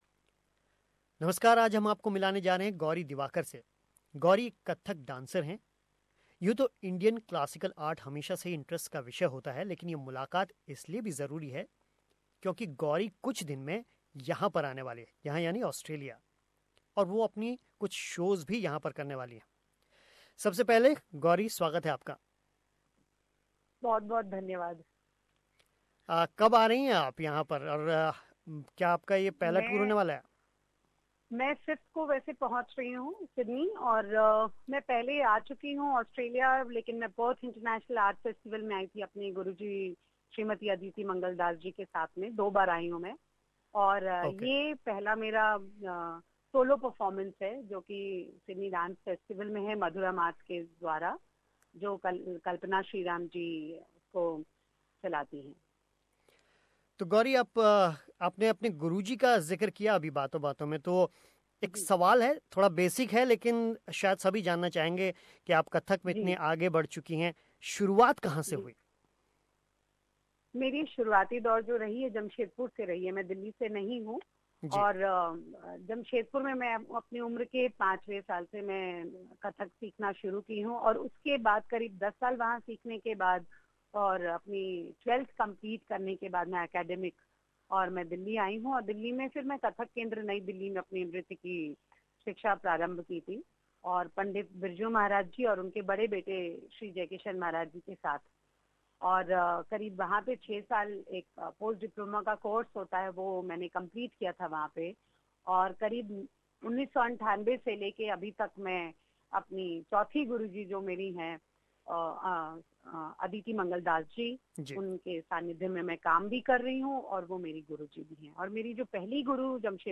यहां सुनिए उनसे पूरी बातचीत.